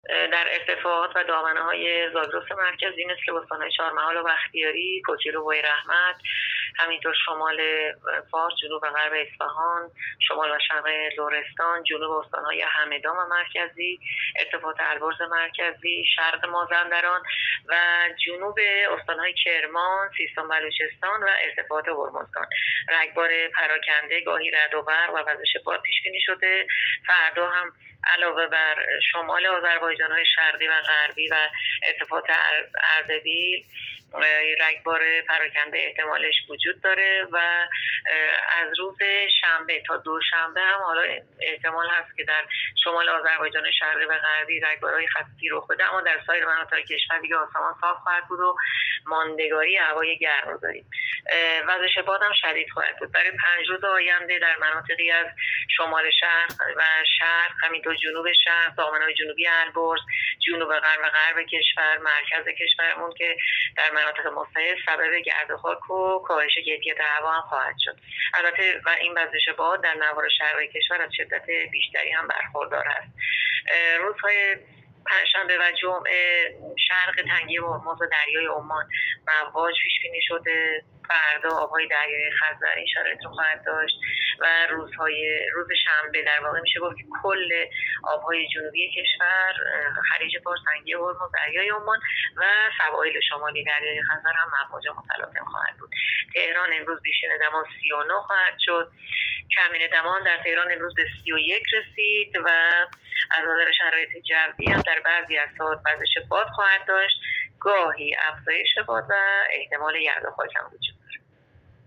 گزارش رادیو اینترنتی پایگاه‌ خبری از آخرین وضعیت آب‌وهوای ۲۶ تیر؛